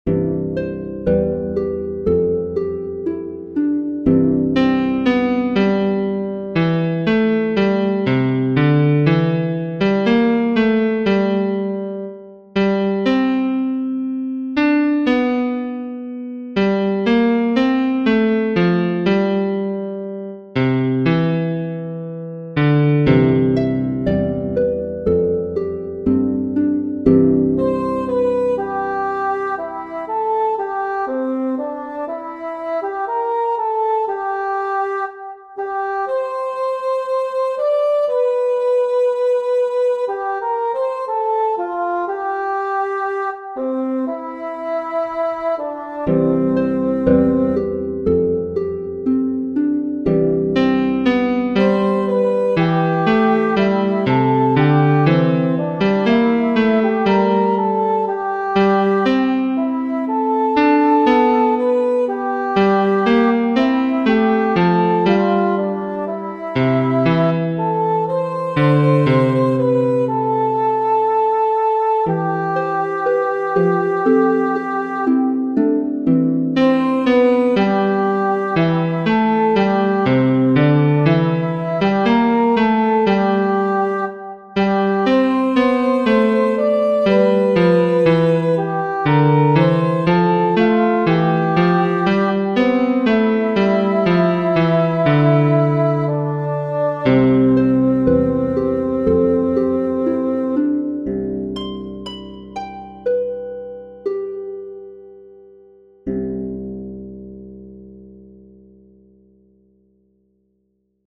Tutti
In this recording, the piano is playing the men’s part and the bassoon is playing the women’s part. The accompaniment sounds like a harp.